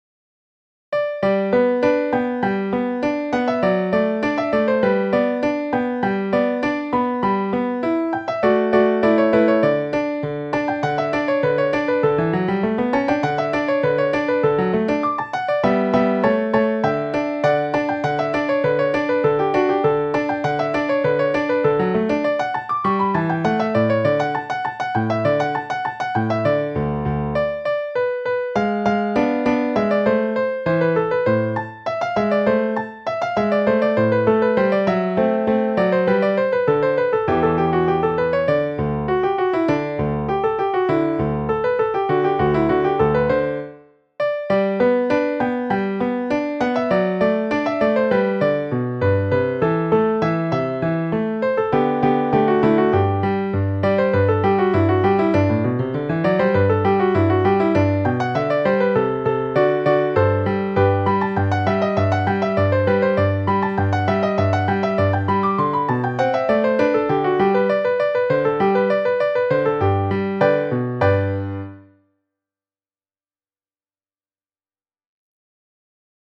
Sonatina in G Major.mp3